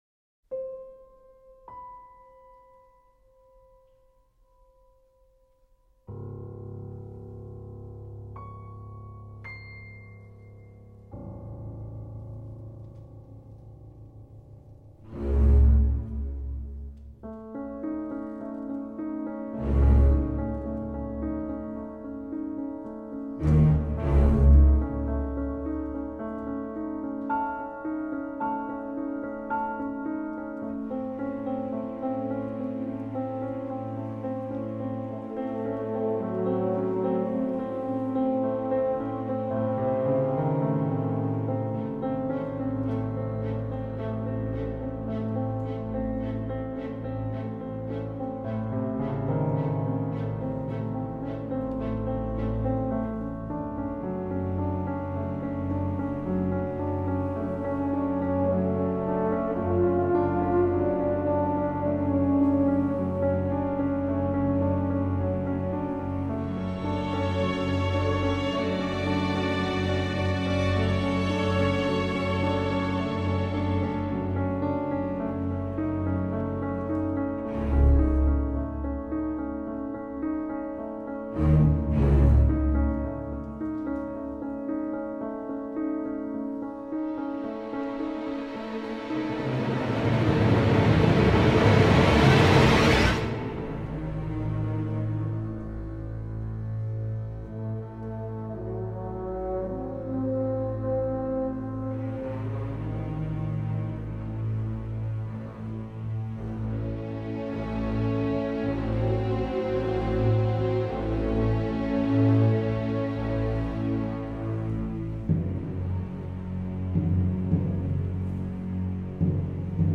трогичная музыка и конец. проводник покинул нас...